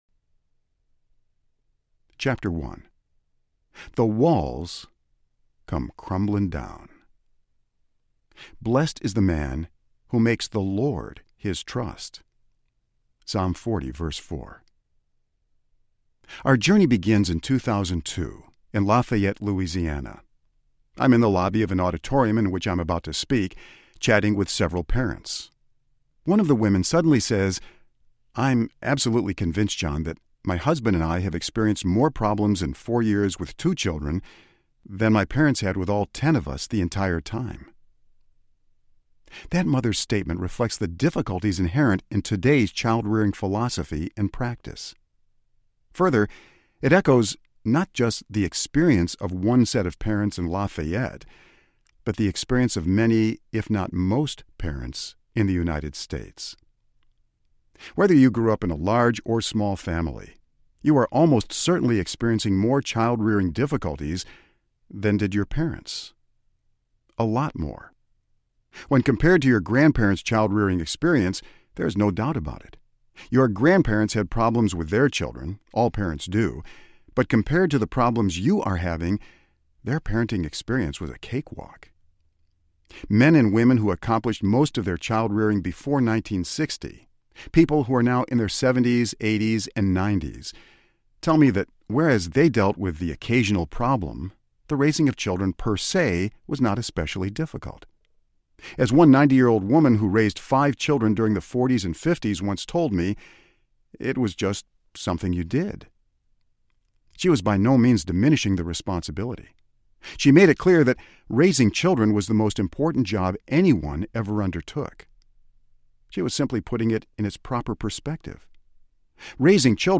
Parenting by The Book Audiobook
7.5 Hrs. – Unabridged